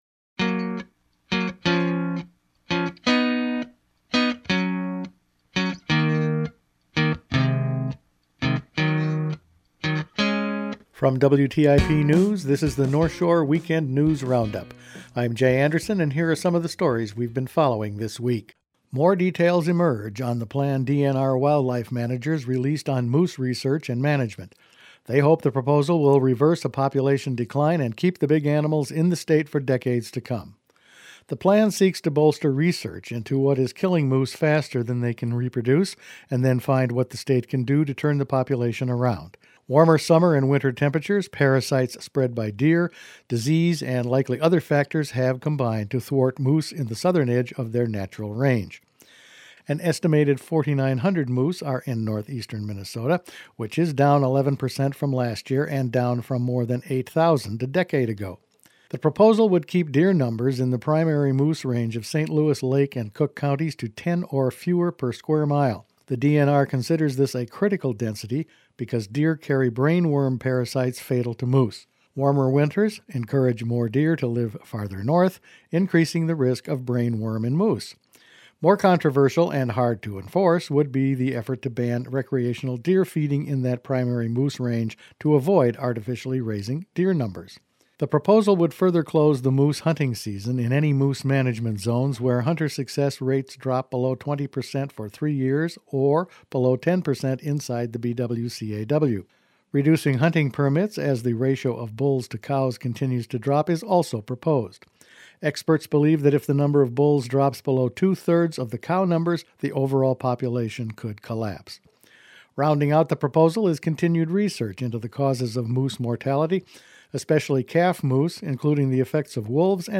Weekend News Roundup for Aug 28